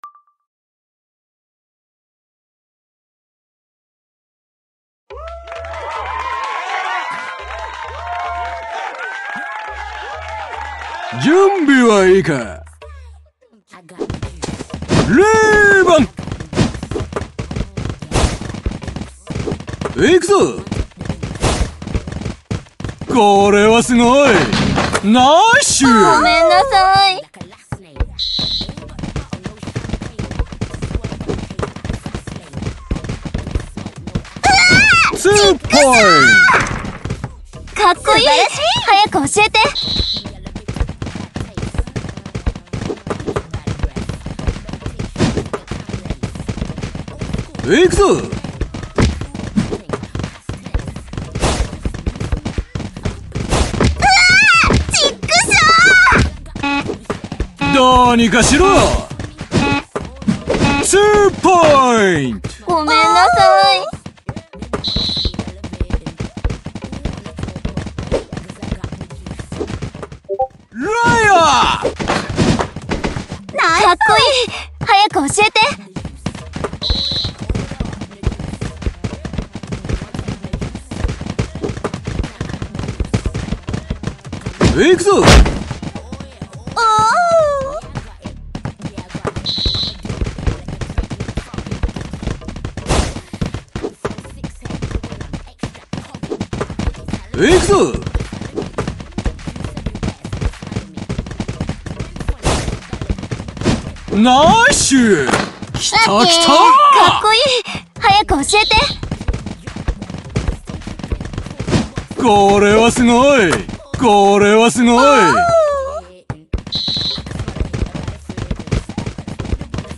3on3対戦ゲーム、アプリ《フィーバーダンク》のプレイ動画です。 実況は無しの試合している光景だけになりますのでご了承を。